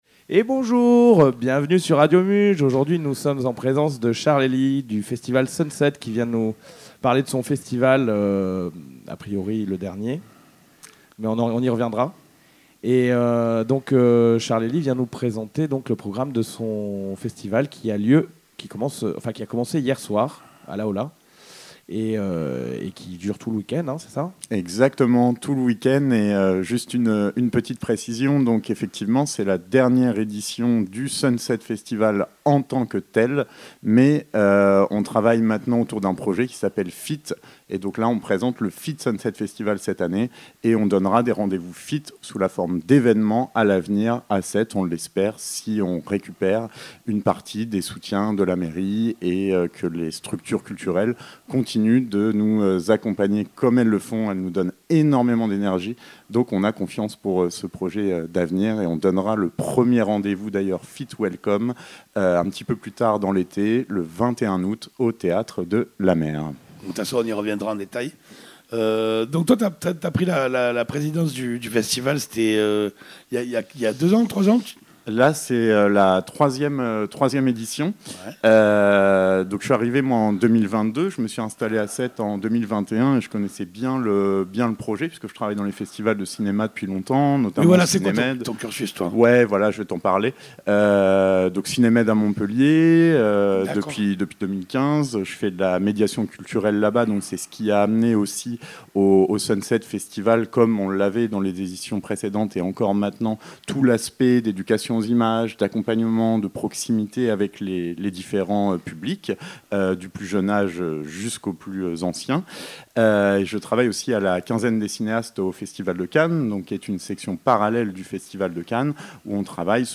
ITW enregistrée et réalisée le mercredi 26 Juin @ Radio Muge Studio.